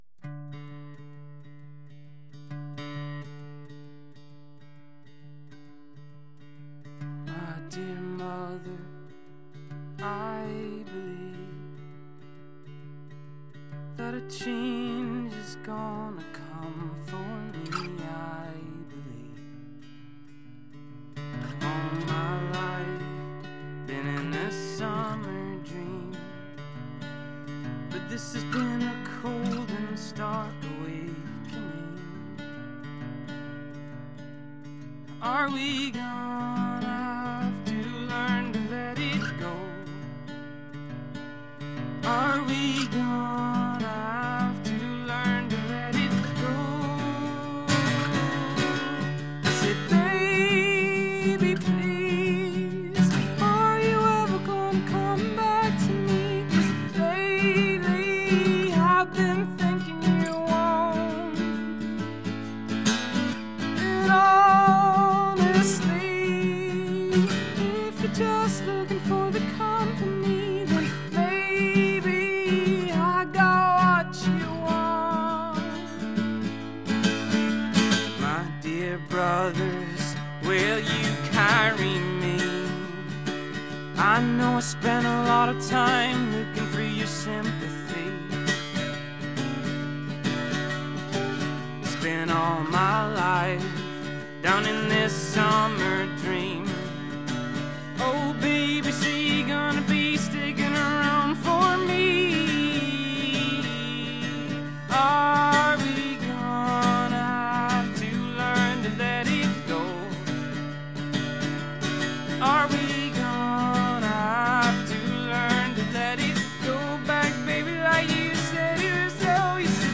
Live session